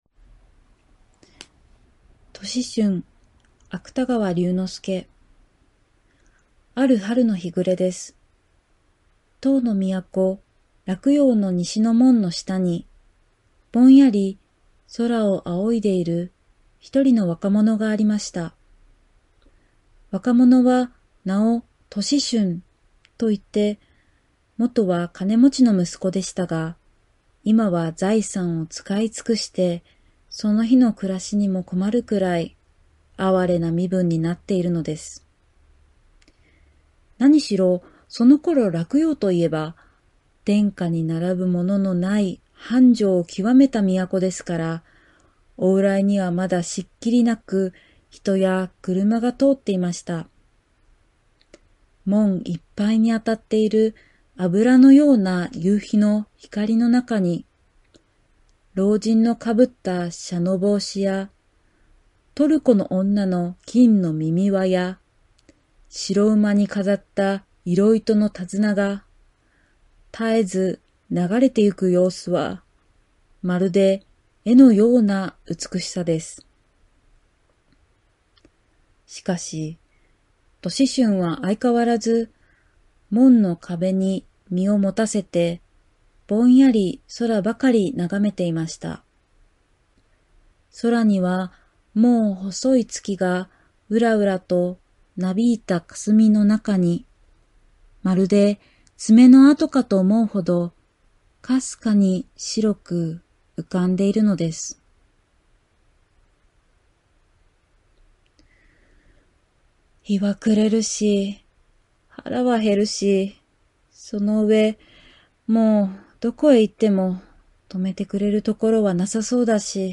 参加してくださった先生たちの音読を紹介します。
音読課題：杜子春（芥川龍之介）
杜子春のみじめな様子が素晴らしく表現されていました。特筆すべきなのは、文章中にちりばめられた絶妙な間（ポーズ）！